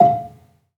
Gambang-F4-f.wav